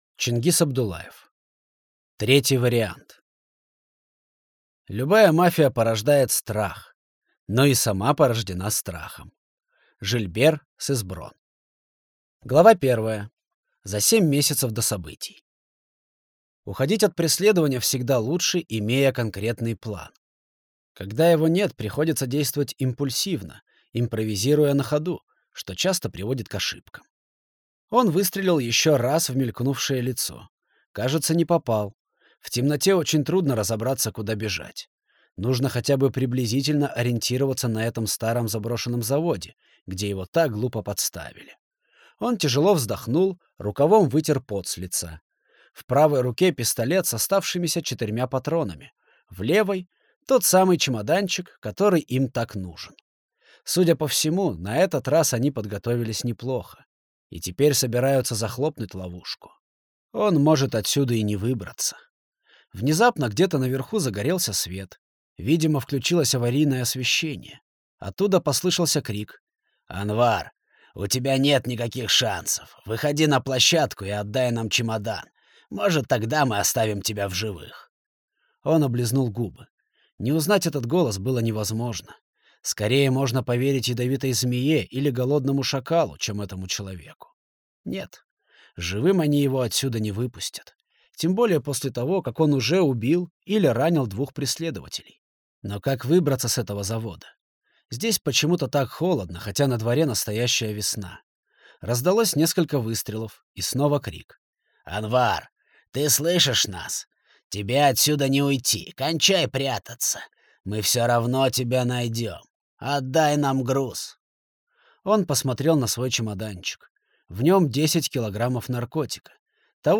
Аудиокнига Третий вариант | Библиотека аудиокниг